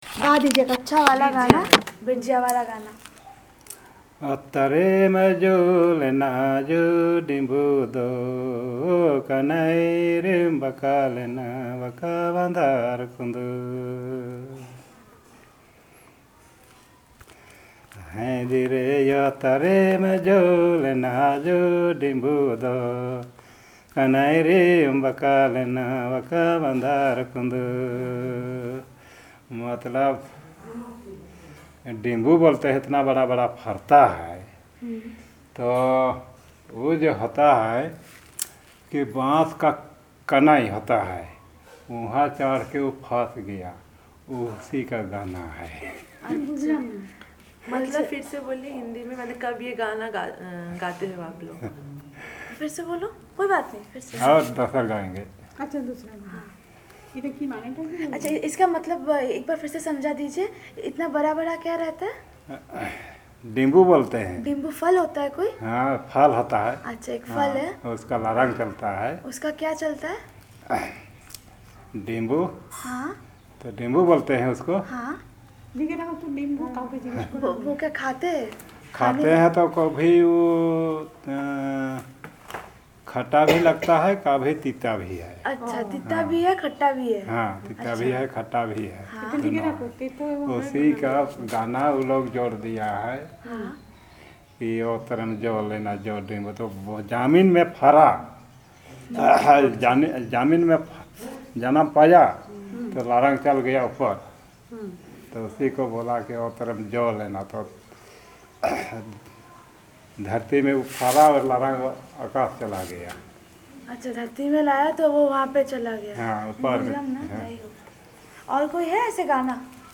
Performance of folk and traditional songs